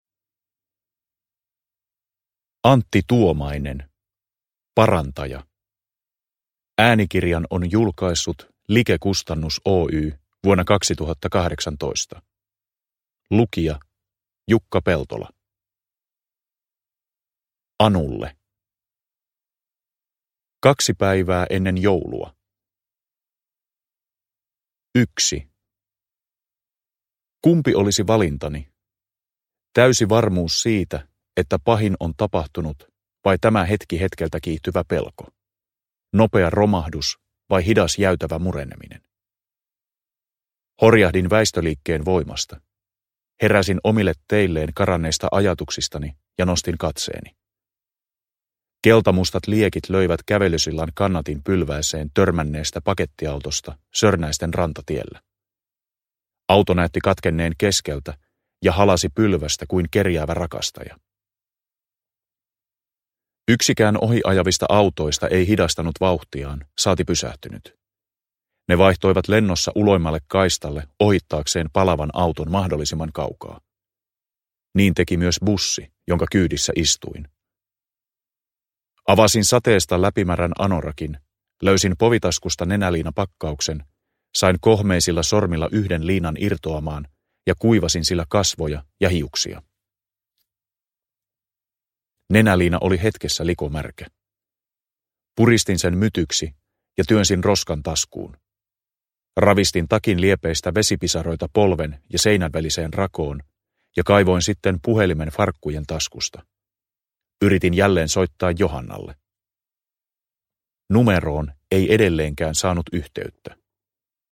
Parantaja – Ljudbok – Laddas ner